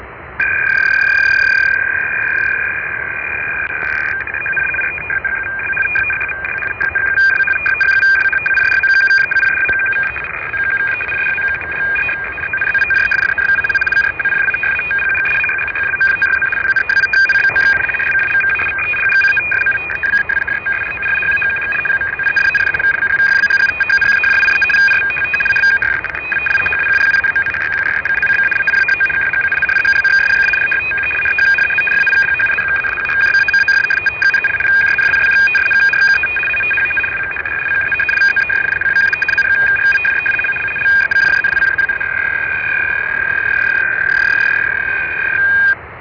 Начало » Записи » Записи радиопереговоров - NATO и союзники